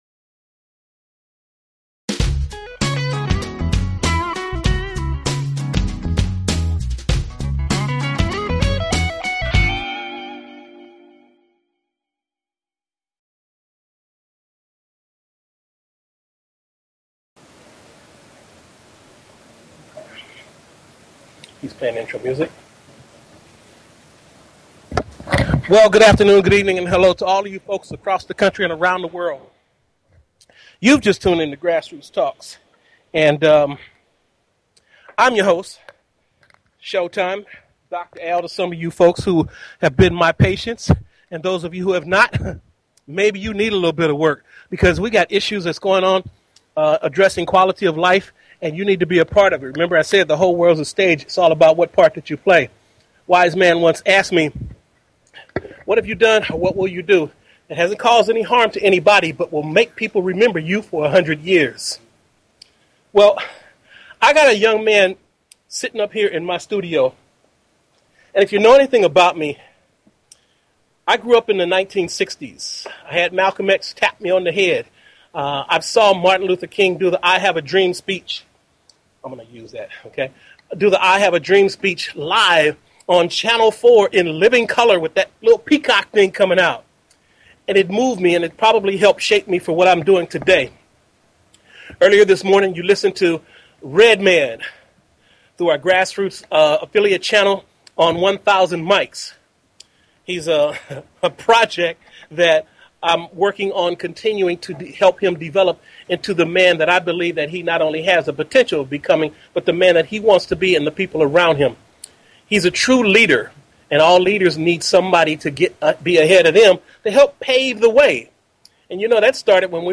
Talk Show Episode, Audio Podcast, Grassroots_Talks and Courtesy of BBS Radio on , show guests , about , categorized as